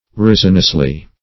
resinously - definition of resinously - synonyms, pronunciation, spelling from Free Dictionary Search Result for " resinously" : The Collaborative International Dictionary of English v.0.48: Resinously \Res"in*ous*ly\, adv. By means, or in the manner, of resin.